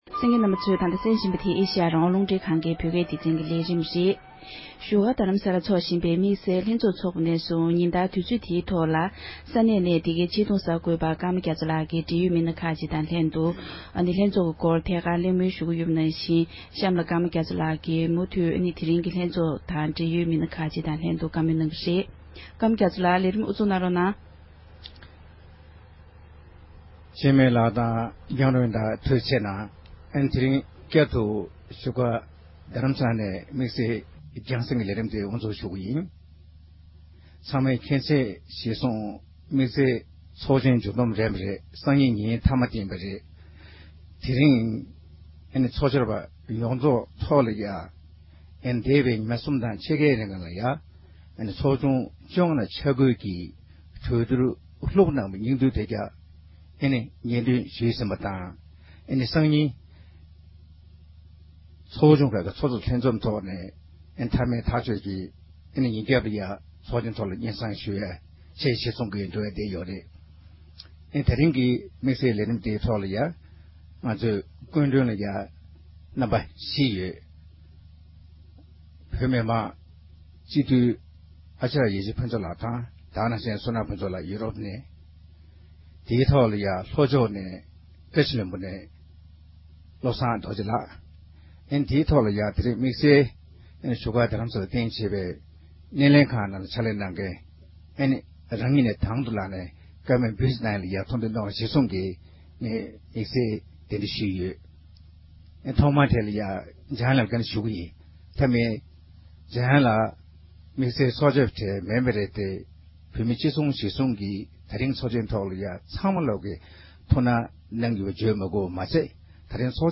ཉིན་ལྔ་པའི་རྗེས་ཚོགས་བཅར་བའི་ལྷན་བགྲོ་གླེང༌།